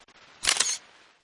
Camera Shutter sound
(This is a lofi preview version. The downloadable version will be in full quality)
JM_Tesla_Lock-Sound_Camera-Shutter_Watermark.mp3